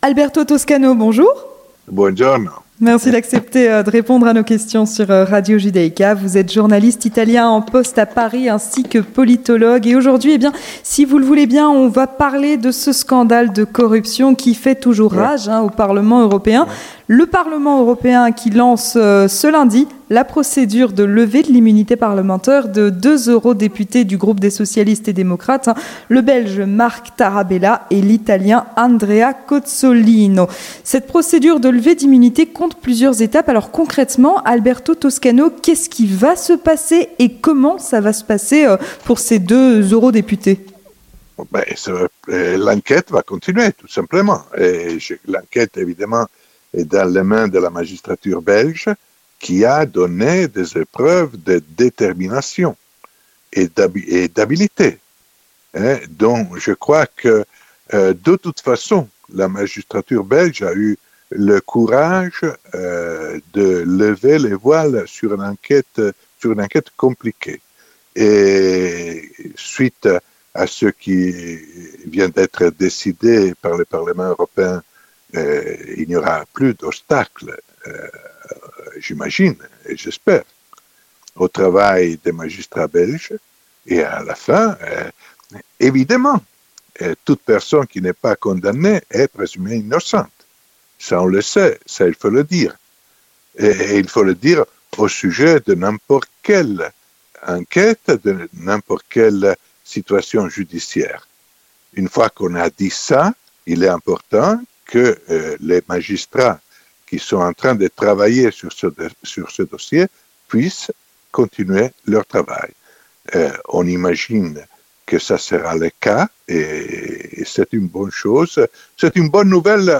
L'entretien du 18h